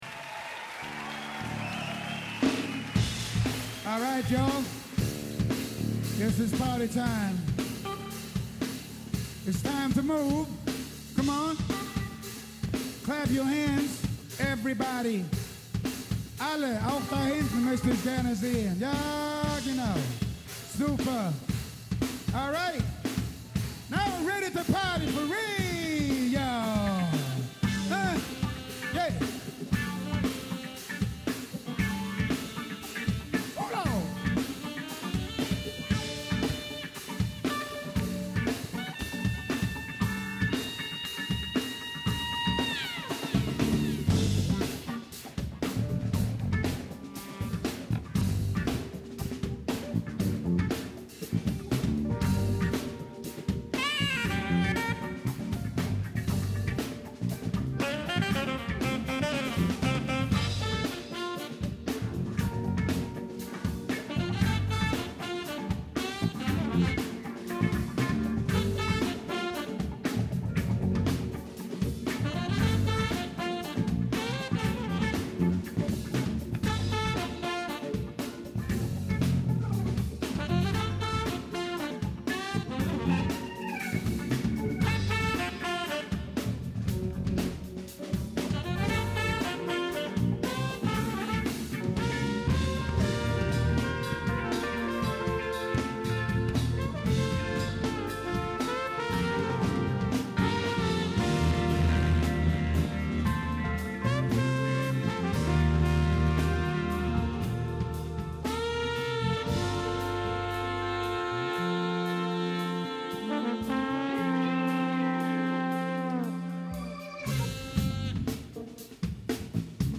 il trombonista svedese